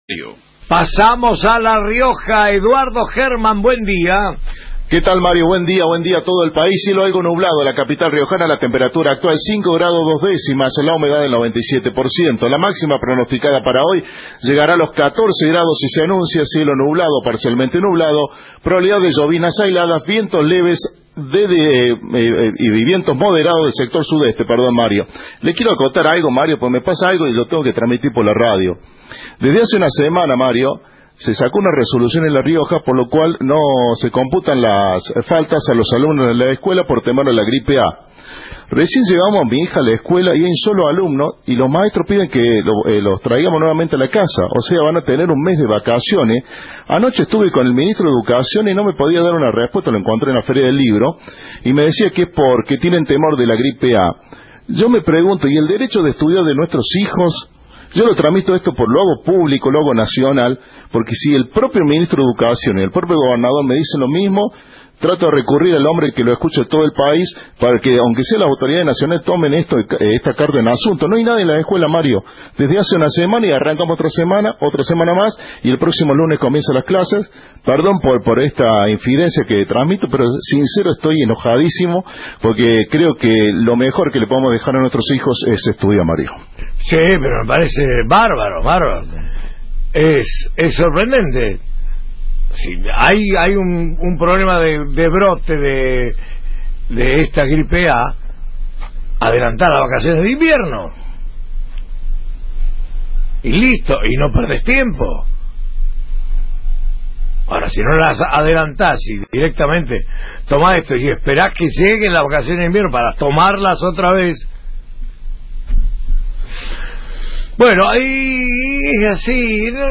Los chicos van a estar un mes sin clases (Informe
«No les toman asistencia ni dan contenidos nuevos», contó a Cadena 3 una mamá y agregó: «En la escuela de mis nenes había un caso de Gripe A y dijeron que dependía de cada padre si querían mandarlos o no».